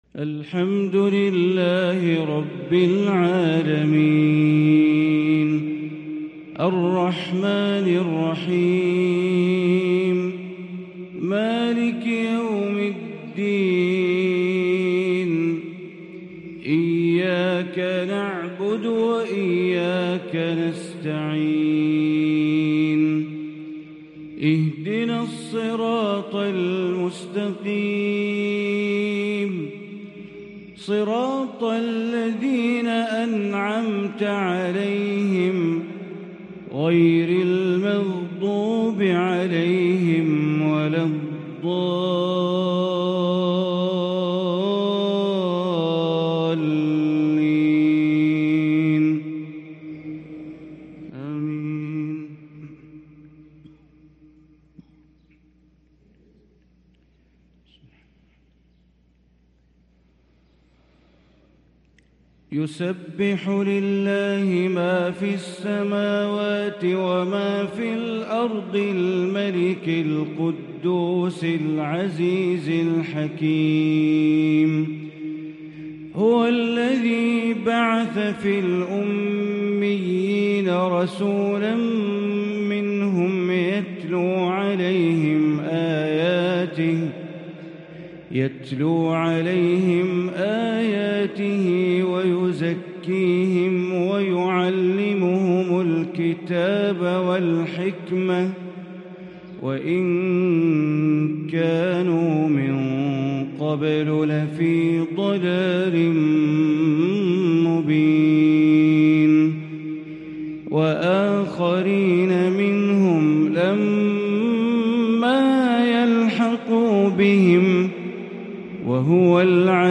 فجر الجمعة 7 محرم 1444هـ سورة الجمعة كاملة | Fajr prayer from Surat AlJumu'ah 5-8-2022 > 1444 🕋 > الفروض - تلاوات الحرمين